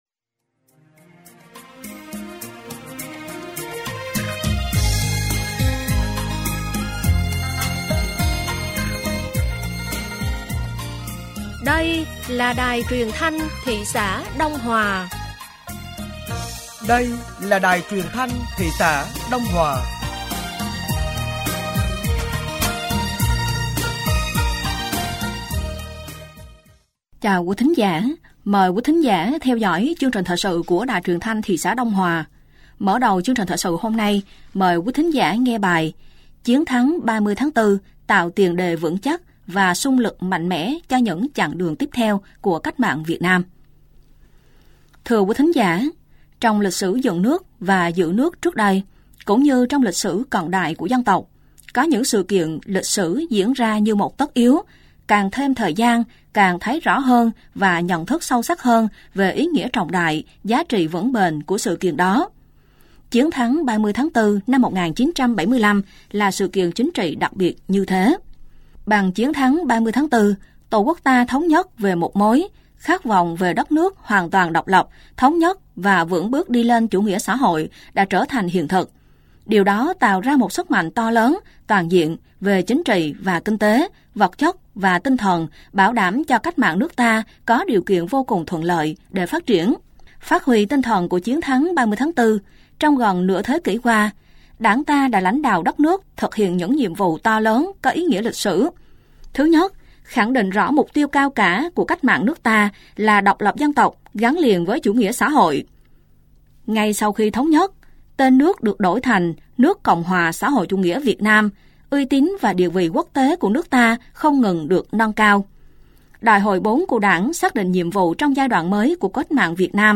Thời sự tối ngày 30 tháng 4 và sáng ngày 01 tháng 5 năm 2025